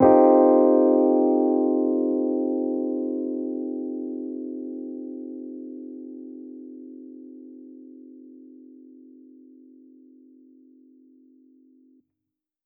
Index of /musicradar/jazz-keys-samples/Chord Hits/Electric Piano 2
JK_ElPiano2_Chord-Cm13.wav